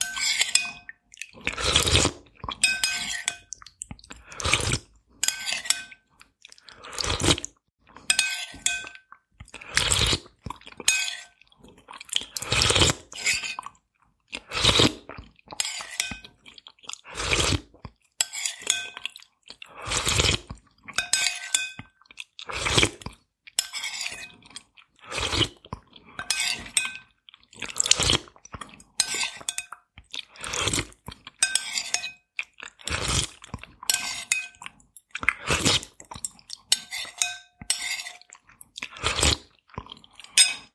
Звук чавкающего человека во время еды супа